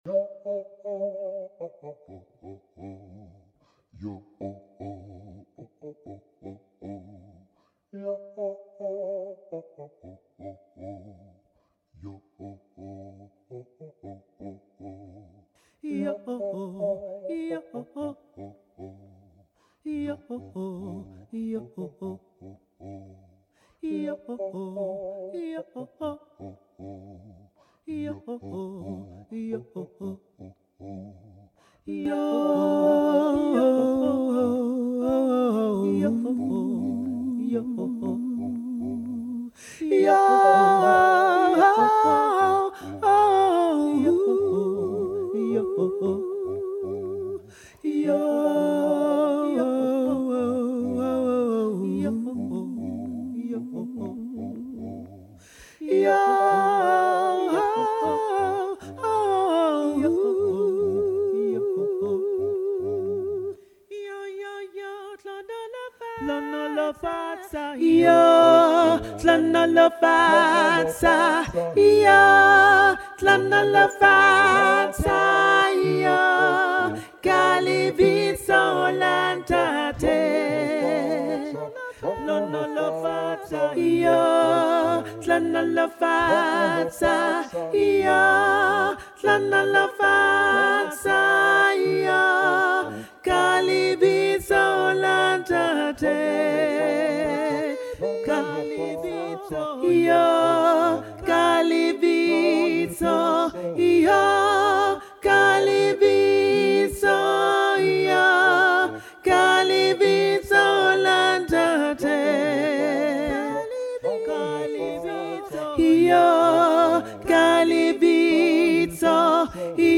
Hlonolofatsa-Alto.mp3